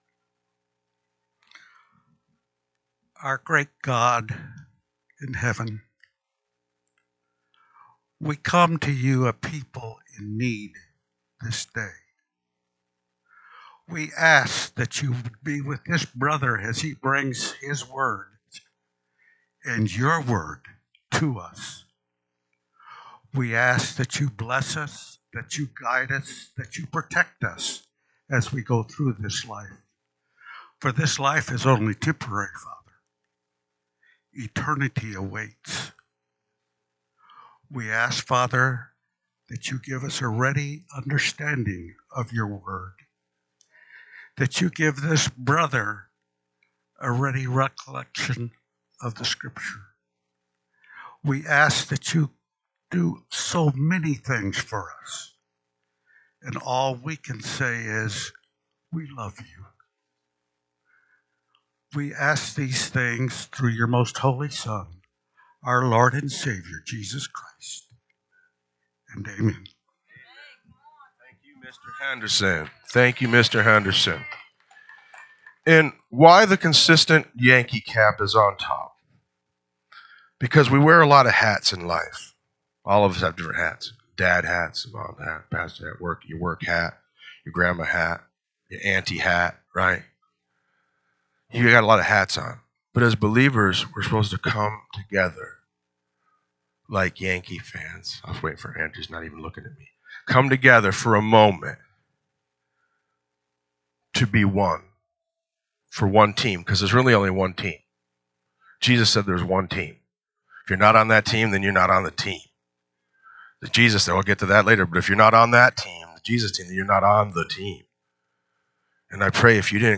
NSCF Sermons Online Acts of the Apostles Chapter 22 Feb 02 2026 | 00:42:23 1x 00:00 / 00:42:23 Subscribe Share RSS Feed Share Link Embed